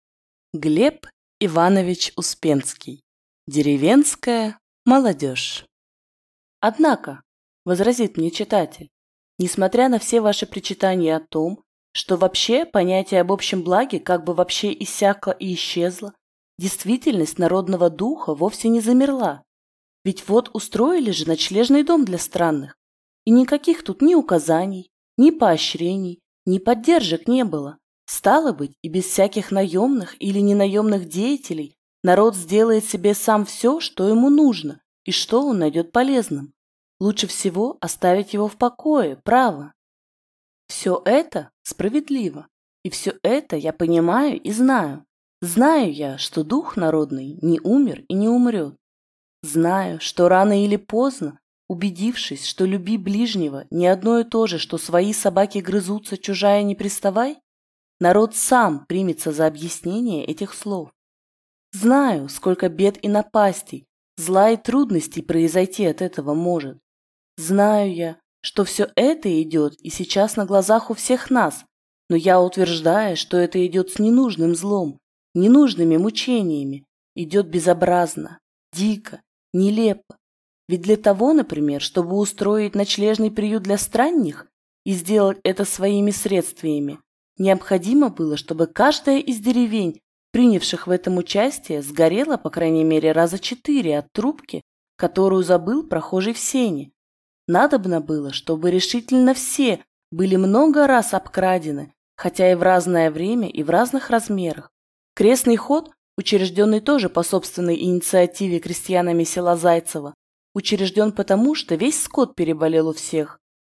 Аудиокнига Деревенская молодежь | Библиотека аудиокниг